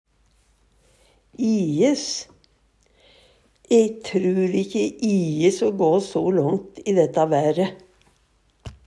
ijes - Numedalsmål (en-US)